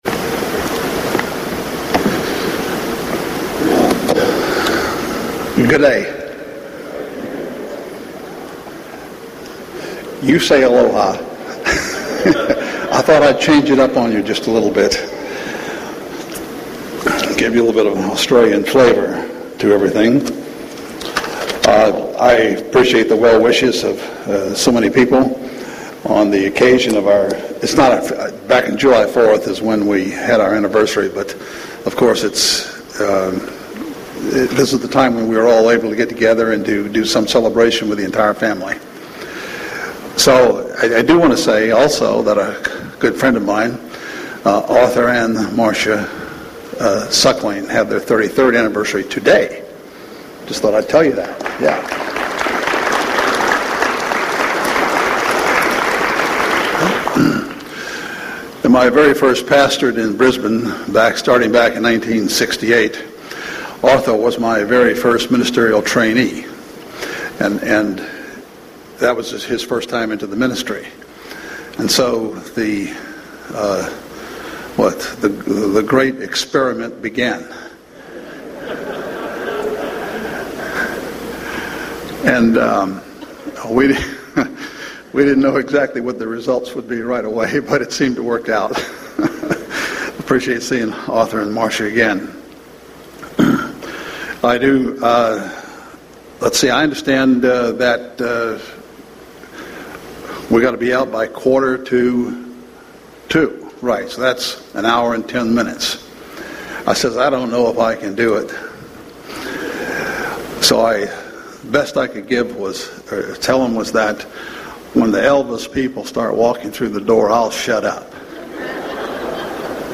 This sermon was given at the Maui, Hawaii 2015 Feast site.